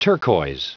Prononciation du mot turquois en anglais (fichier audio)
Prononciation du mot : turquois